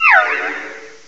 cry_not_swirlix.aif